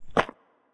multiple step sounds